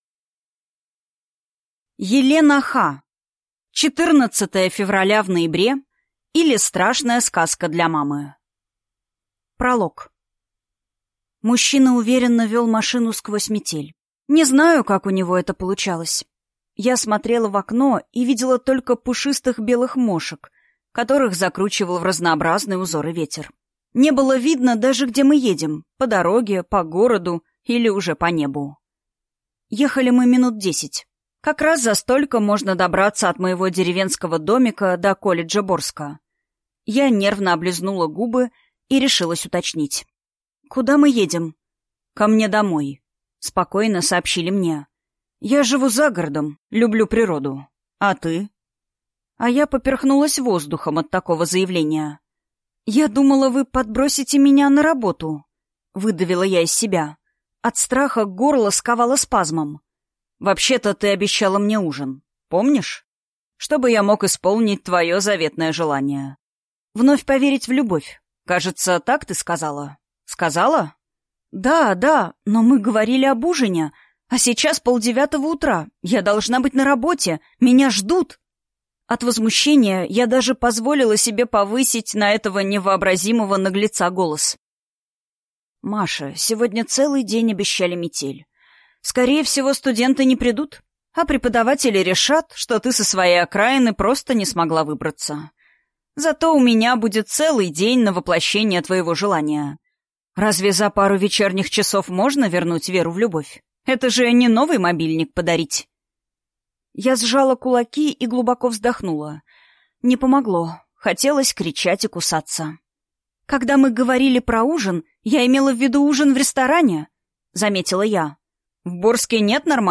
Аудиокнига 14 февраля в ноябре, или Страшная сказка для мамы | Библиотека аудиокниг